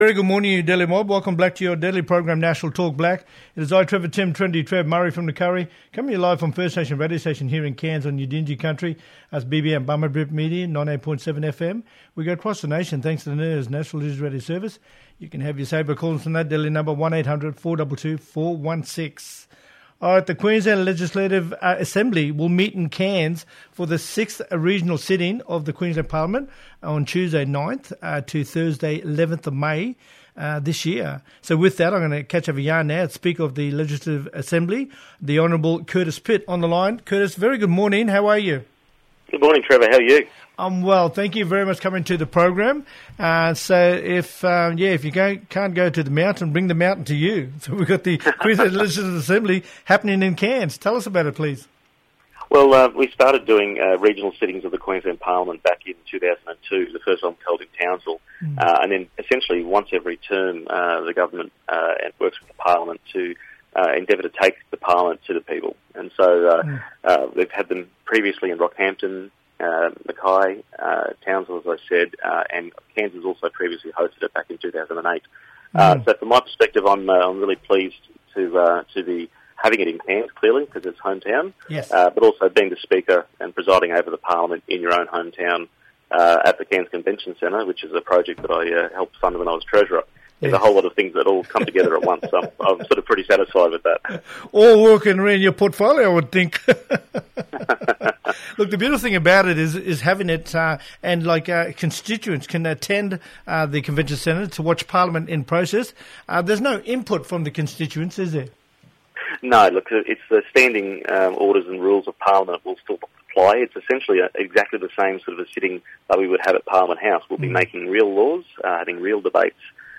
Curtiss Pitt, Speaker of the Legislative Assembly, talking about the Queensland Parliament coming to Far North Queensland in May.